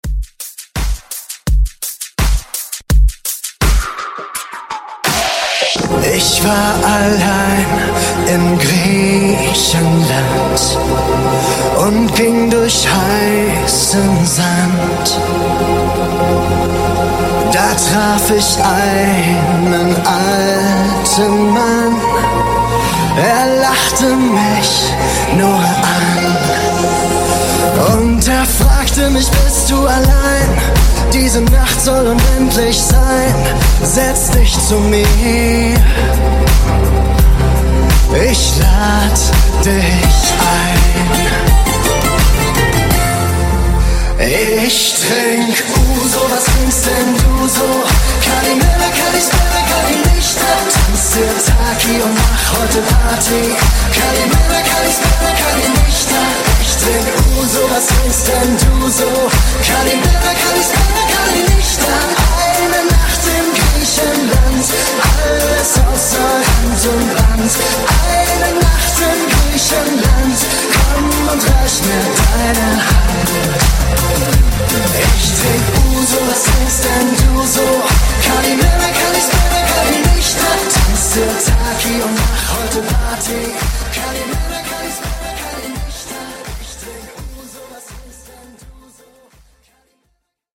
Genres: 90's , HIPHOP , RE-DRUM
Clean BPM: 110 Time